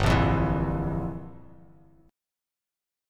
Gm13 chord